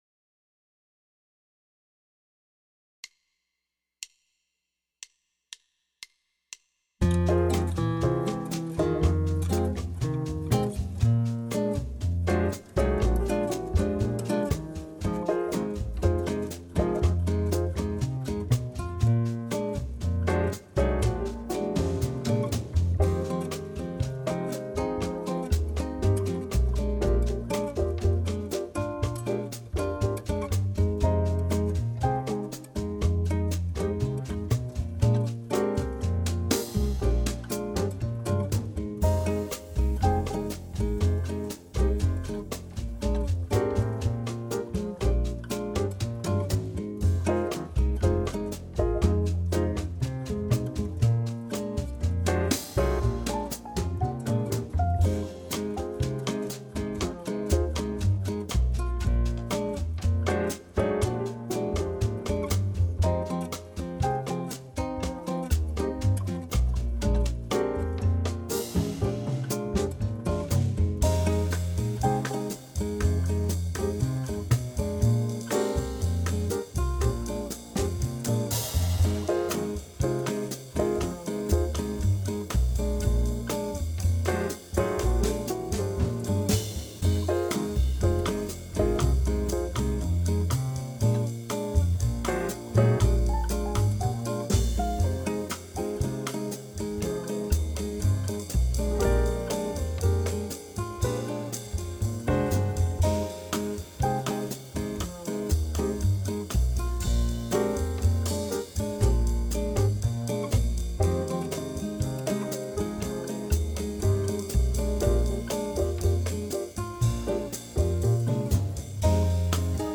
MP3 Backing Track